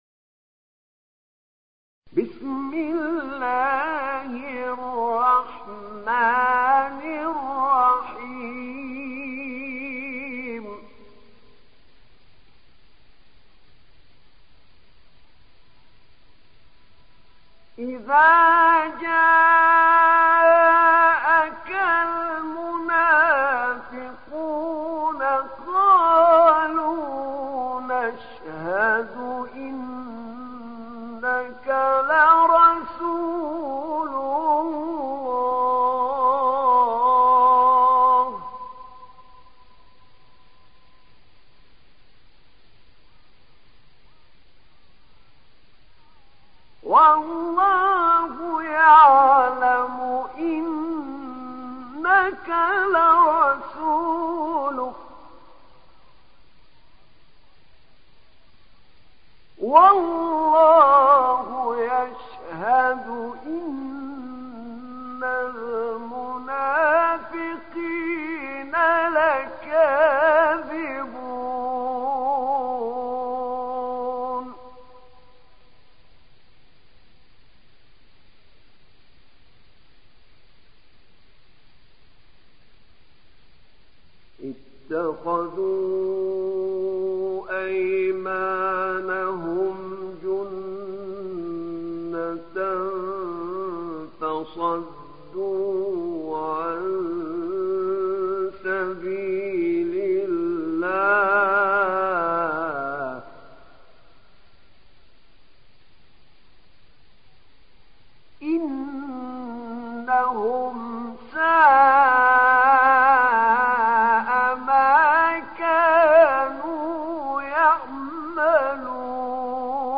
تحميل سورة المنافقون mp3 بصوت أحمد نعينع برواية حفص عن عاصم, تحميل استماع القرآن الكريم على الجوال mp3 كاملا بروابط مباشرة وسريعة